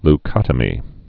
(l-kŏtə-mē)